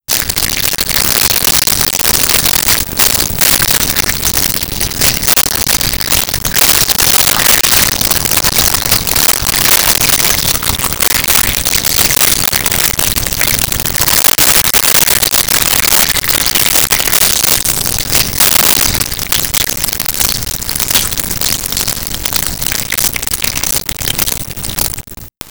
Liquid Pour 01
Liquid Pour 01.wav